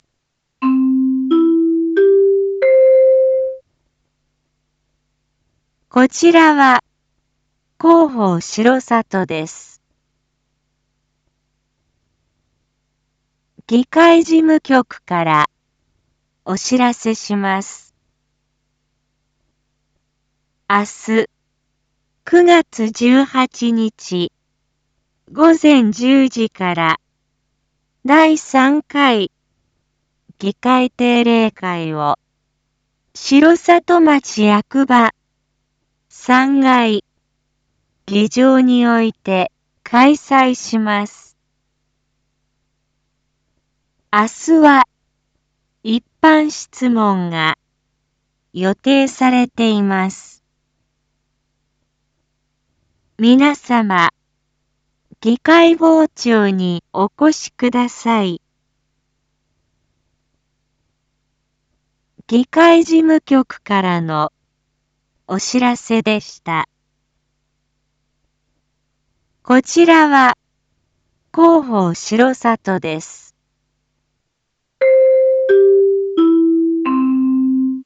Back Home 一般放送情報 音声放送 再生 一般放送情報 登録日時：2024-09-17 19:01:19 タイトル：第３回議会定例会③ インフォメーション：こちらは広報しろさとです。